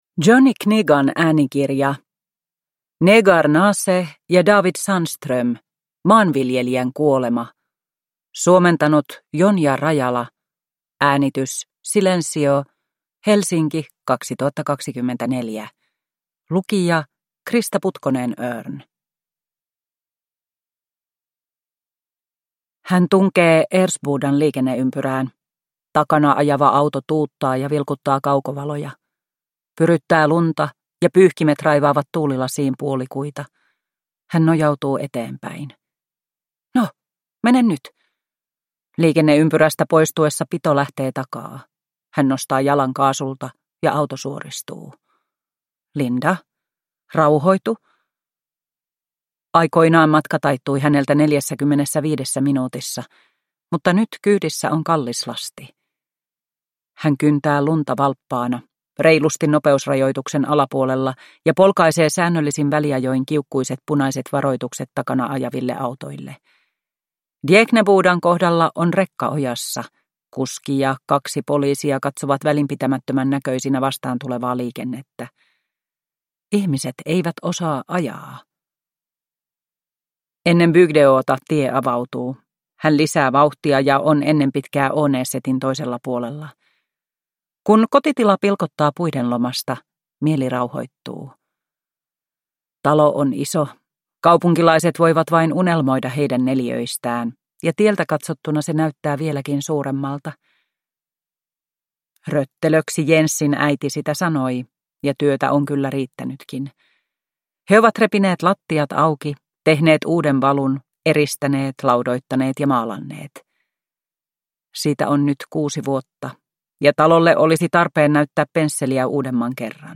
Maanviljelijän kuolema (ljudbok) av Negar Naseh